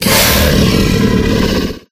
Monster7.ogg